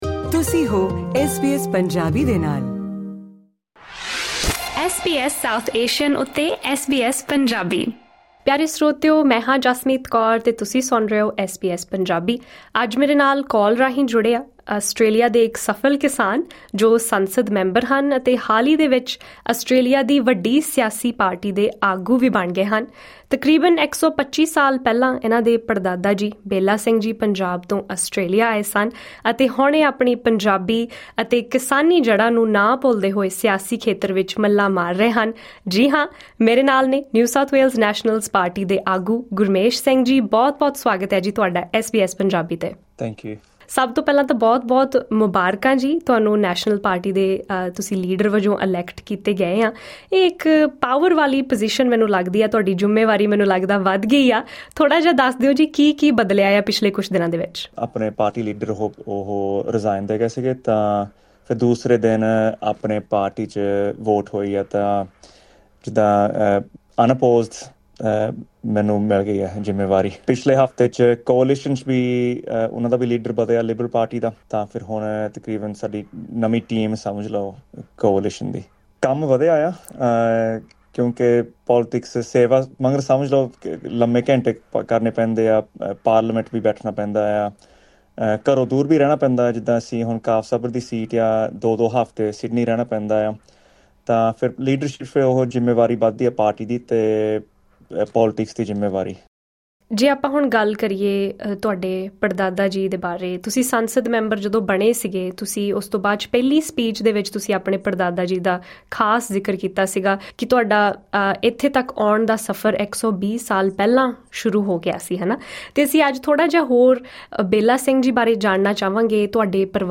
ਨਿਊ ਸਾਊਥ ਵੇਲਜ਼ ਨੈਸ਼ਨਲਜ਼ ਪਾਰਟੀ ਦੀ ਵਾਗ ਡੋਰ ਸੰਭਾਲ ਰਹੇ ਕਾਫ਼ਸ ਹਾਰਬਰ ਸੀਟ ਦੇ ਐਮ ਪੀ ਗੁਰਮੇਸ਼ ਸਿੰਘ ਨੇ ਐਸ ਬੀ ਐਸ ਪੰਜਾਬੀ ਨਾਲ ਖਾਸ ਗੱਲਬਾਤ ਦੌਰਾਨ ਆਸਟ੍ਰੇਲੀਆ ਵਿੱਚ ਪਰਵਾਸ, ਹਾਊਸਿੰਗ, ਕਿਸਾਨੀ ਅਤੇ ਕਈ ਹੋਰ ਚਲੰਤ ਮਾਮਲਿਆਂ ਉਤੇ ਗੱਲਬਾਤ ਕੀਤੀ। ਉਨ੍ਹਾਂ ਦੱਸਿਆ ਕਿ ਆਸਟ੍ਰੇਲੀਆ ਲਈ ਪਰਵਾਸੀ ਜ਼ਰੂਰੀ ਹਨ ਪਰ ਸੀਮਤ ਗਿਣਤੀ ਵਿੱਚ, ਜਿਸ ਨਾਲ ਬੁਨਿਆਦੀ ਜ਼ਰੂਰਤਾਂ ਦੀ ਥੋੜ ਨਾਂ ਹੋਵੇ।